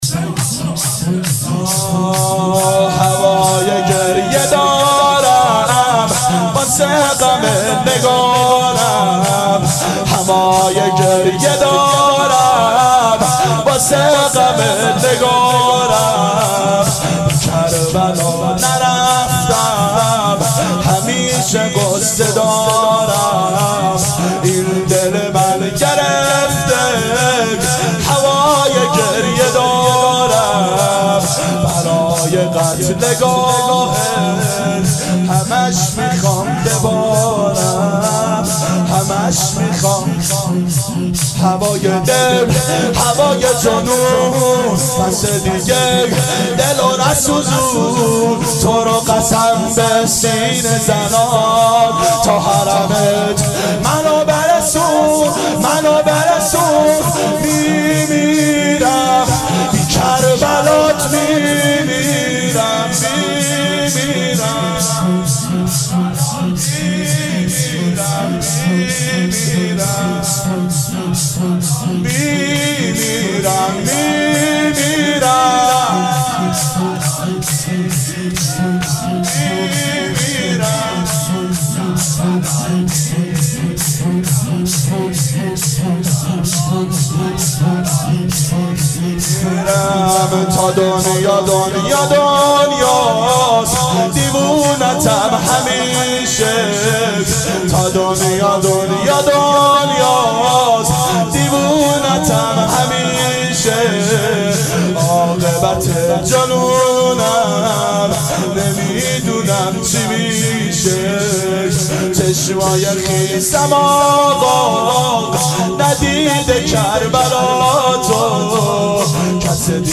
دهه اول صفر سال 1390 هیئت شیفتگان حضرت رقیه س شب سوم (شام غریبان)